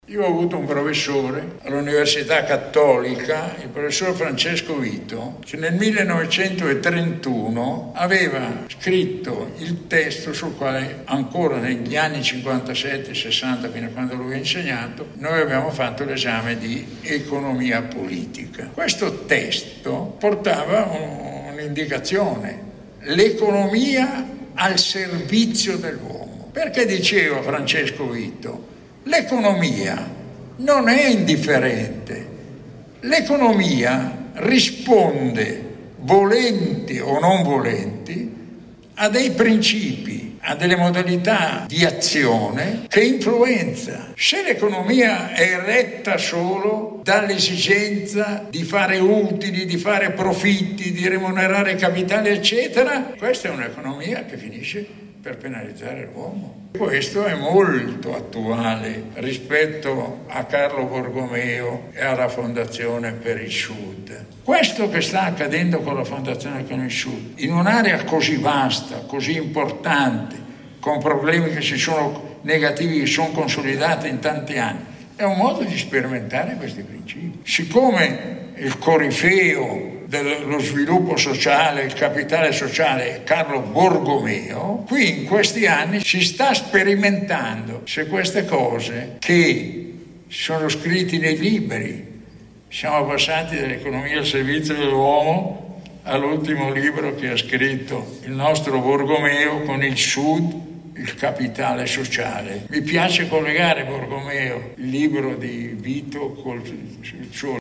Partendo da questa premessa, Fondazione con il Sud ha organizzato al Rione Parco Verde di Caivano, a Napoli, l’incontro “Un futuro già visto”.
Ecco la riflessione di Guzzetti.